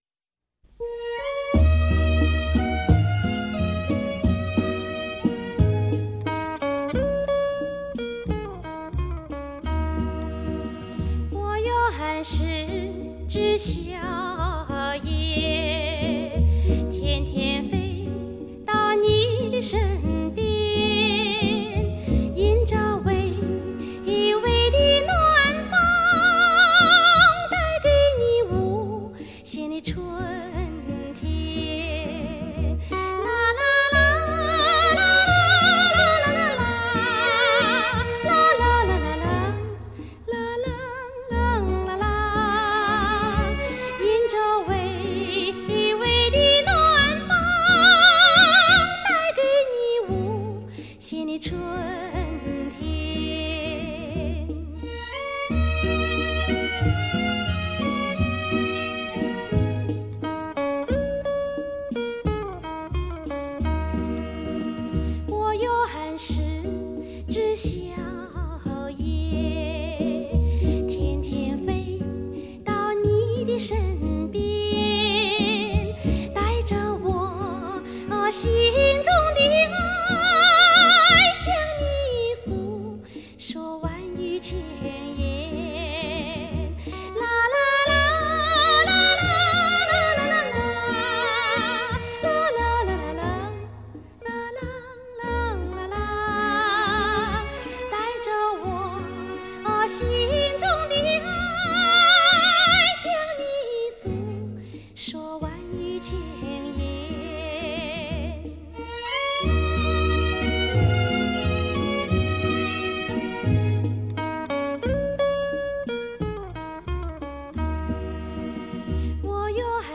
唯有年轻，声音才足够甜润，才最富有光泽。